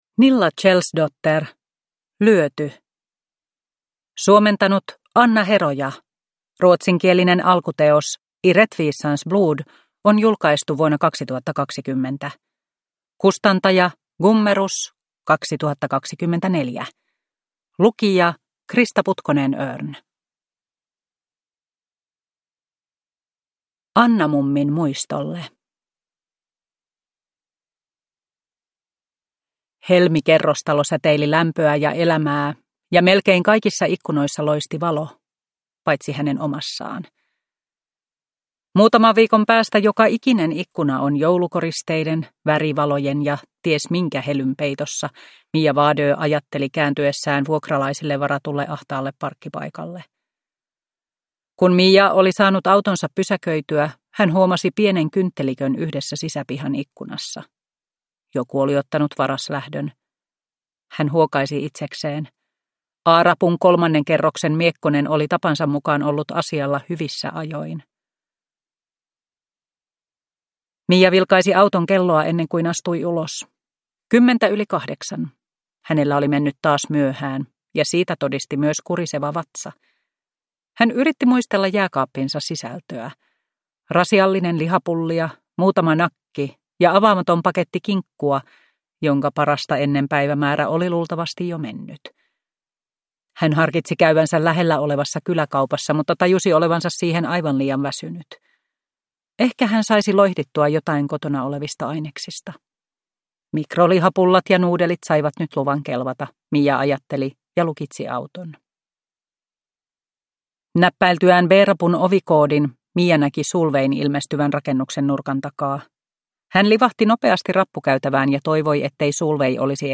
Mija Wadö tutkii 1 (ljudbok) av Nilla Kjellsdotter